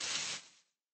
sounds / grass / mining3.ogg
mining3.ogg